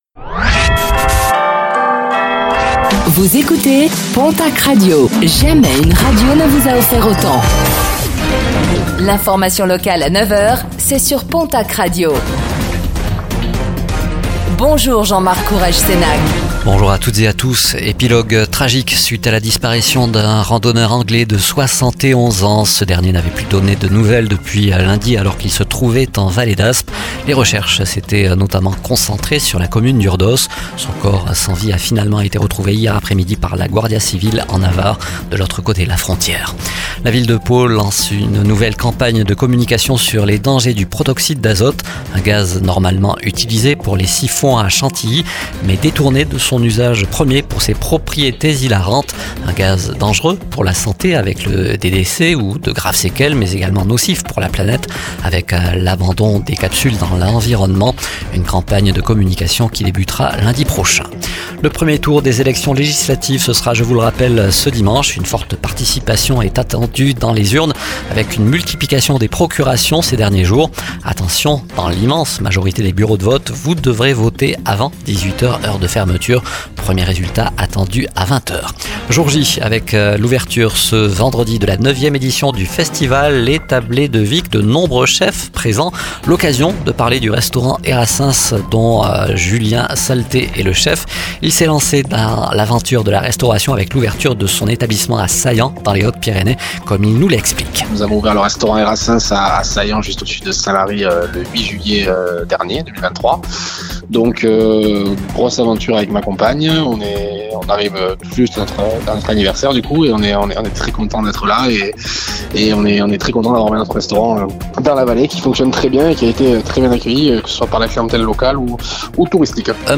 Réécoutez le flash d'information locale de ce vendredi 28 juin 2024